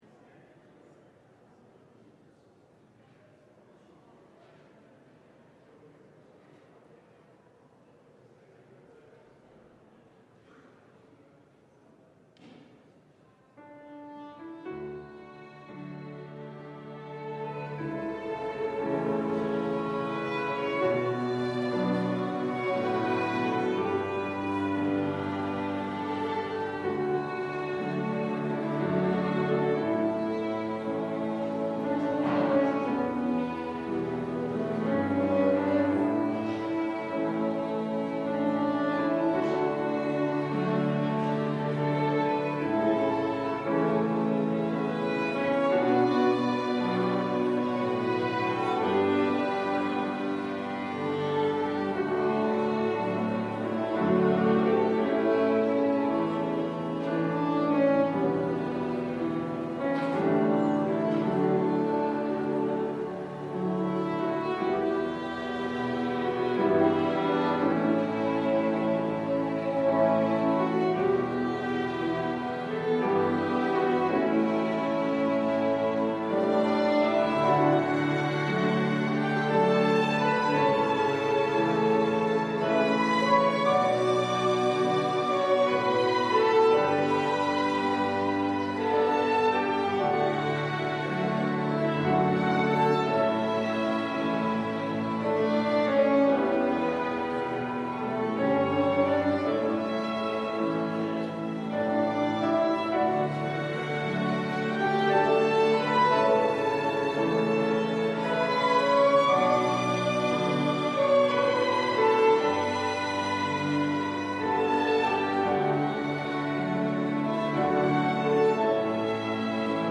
LIVE Midday Worship Service - Who Do You Think You Are?
About The Service: We will have a guest pastor.
Congregational singing—of both traditional hymns and newer ones—is typically supported by our pipe organ.